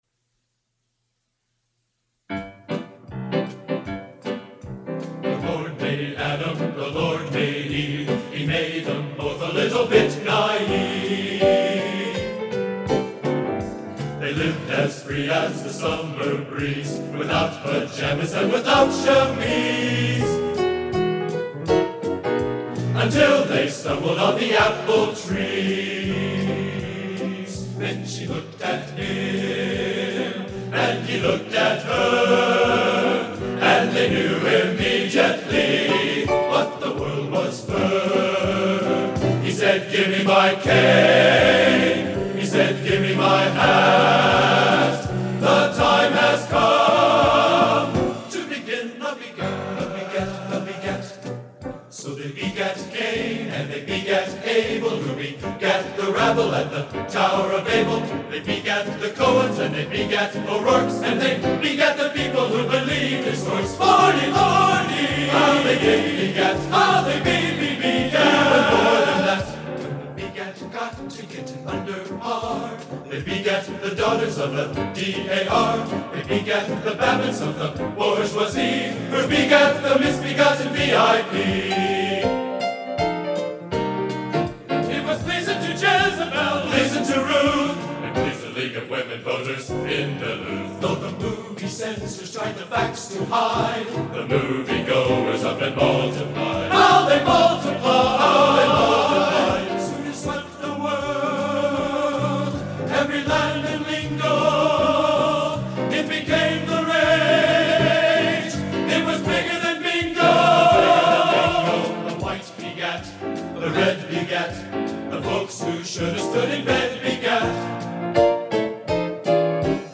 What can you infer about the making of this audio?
Genre: Popular / Standards | Type: Studio Recording